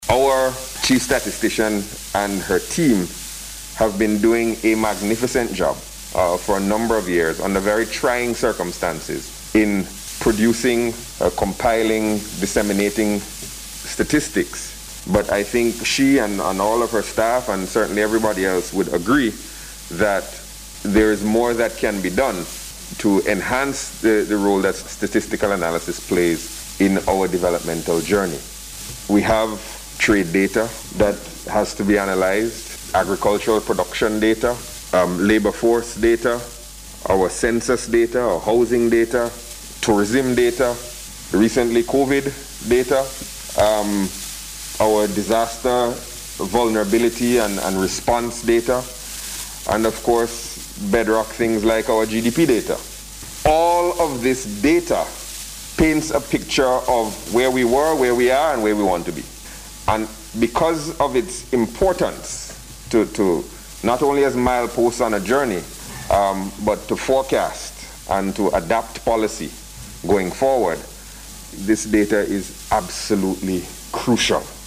Minister of Finance and Economic Planning, Camillo Gonsalves was among several officials who addressed the Launching ceremony this morning at the NIS Conference Facility.